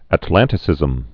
(ăt-lăntĭ-sĭzəm)